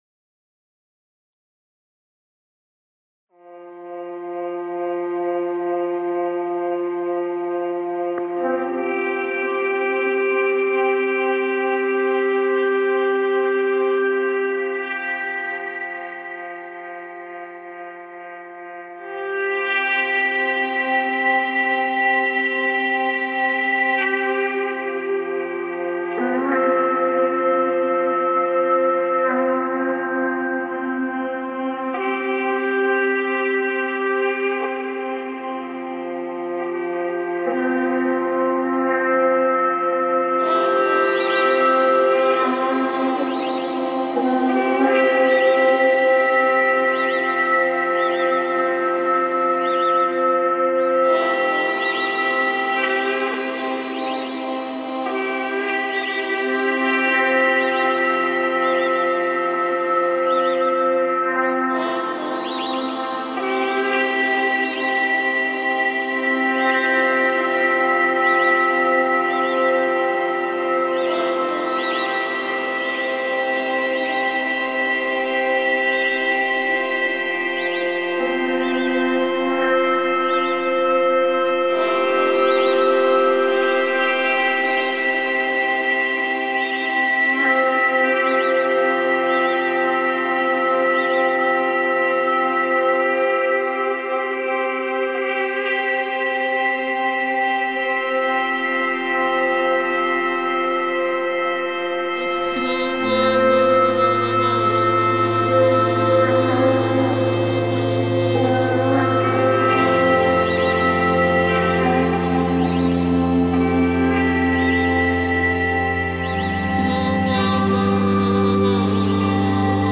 Music for Film
*All Tracks Unmastered*
Tribal / Ambient